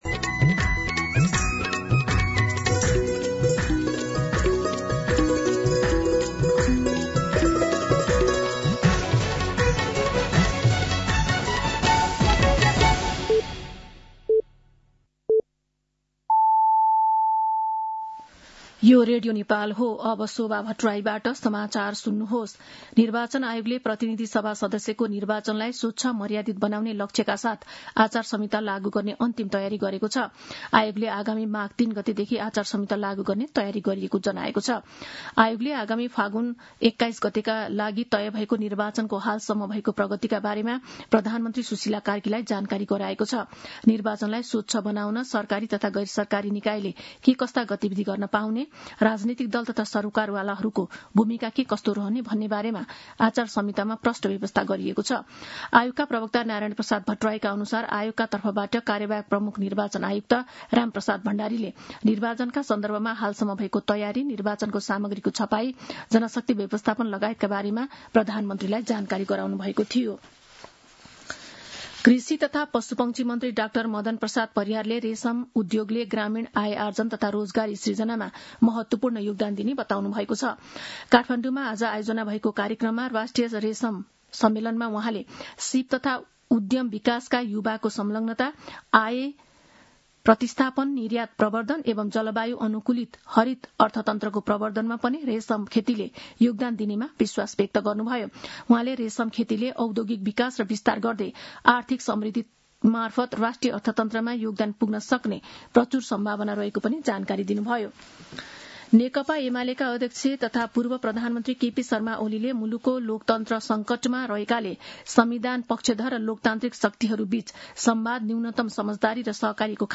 दिउँसो ४ बजेको नेपाली समाचार : २६ पुष , २०८२
4-pm-Nepali-News-2.mp3